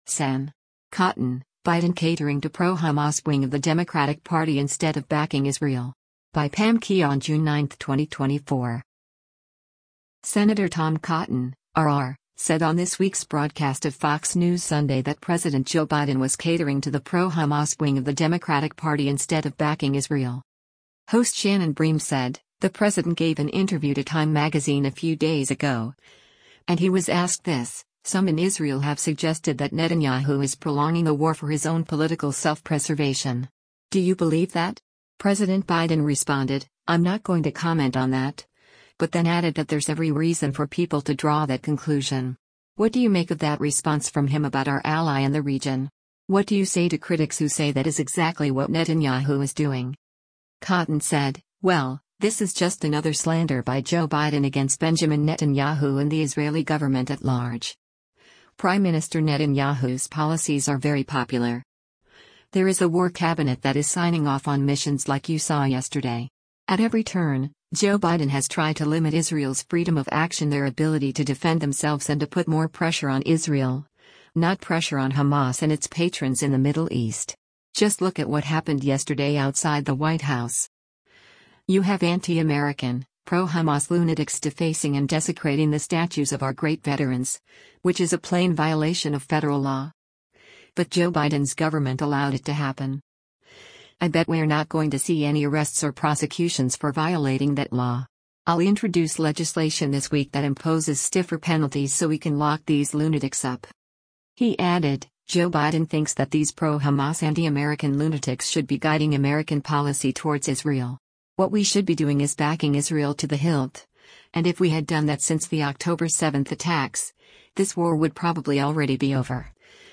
Senator Tom Cotton (R-AR) said on this week’s broadcast of “Fox News Sunday” that President Joe Biden was catering to the “pro-Hamas wing of the Democratic Party instead of backing Israel.”